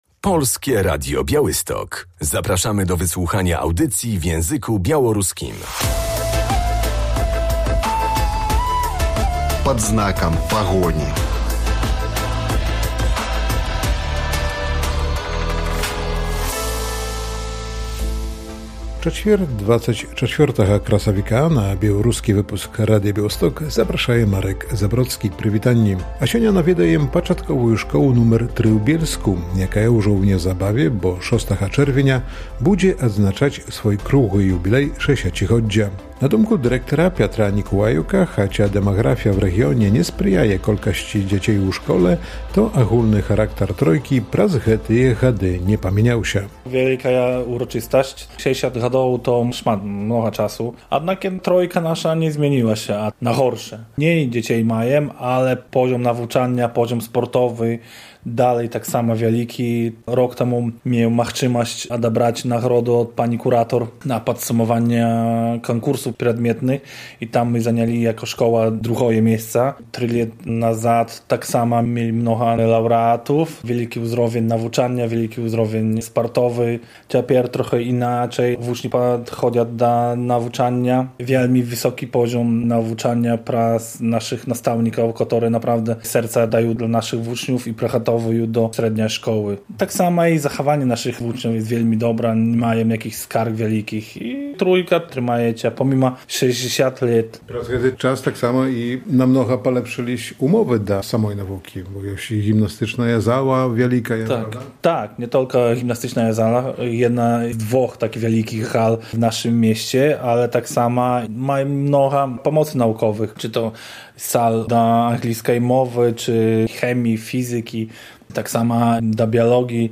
Czy na takiej formie wsparcia skorzystają hotele i pensjonaty z okolic Puszczy Białowieskiej – opowiadają właściciele i menedżerowie obiektów w Białowieży i Narewce.